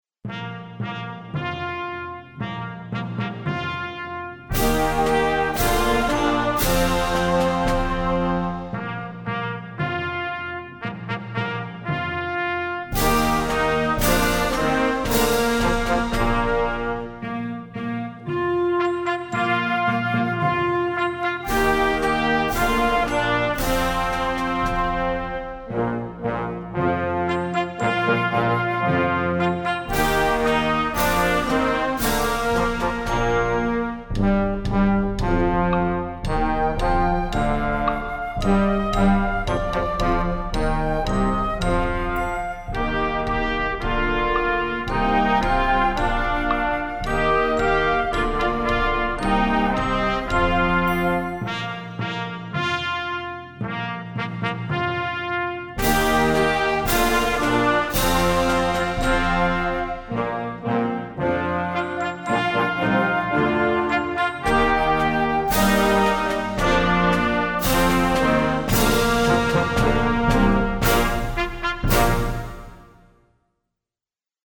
Voicing: Trumpet w/ Band